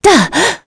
Scarlet-vox-Landing.wav